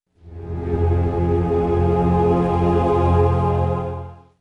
Sound effect
管弦乐老兵被炸死.wav